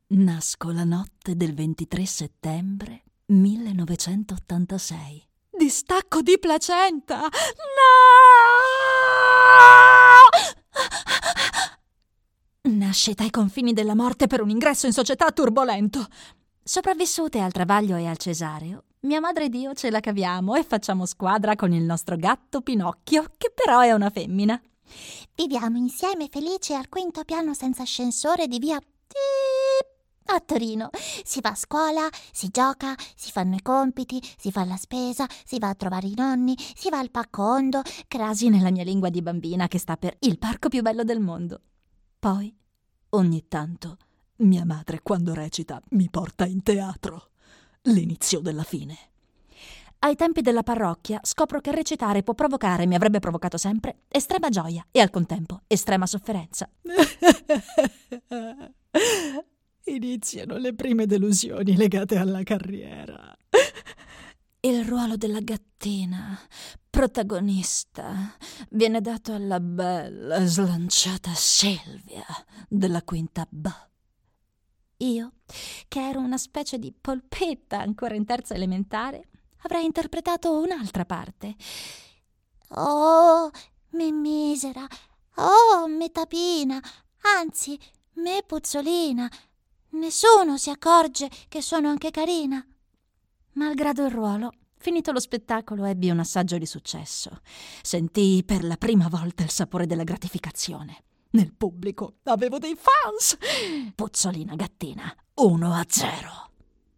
Lettura recitata, voci varie